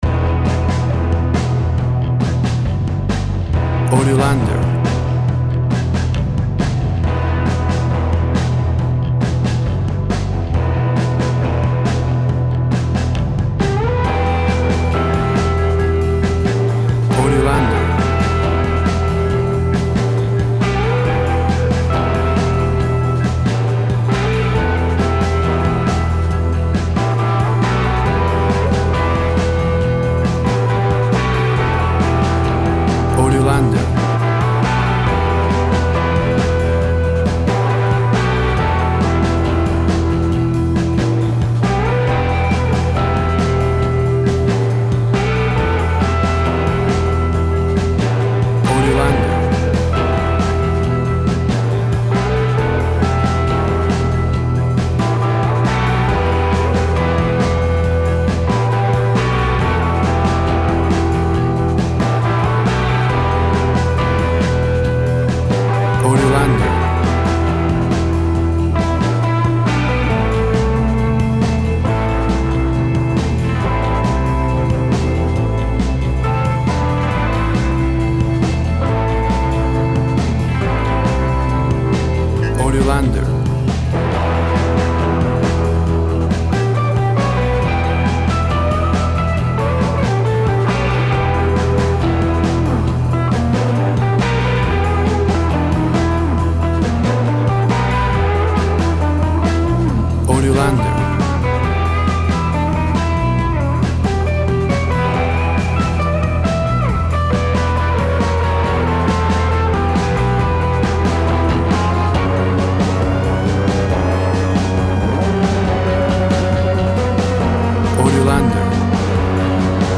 Surf music.
Tempo (BPM) 137